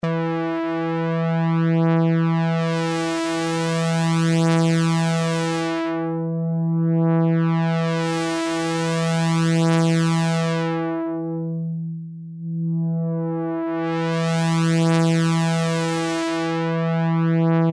TRI+Cutoff.mp3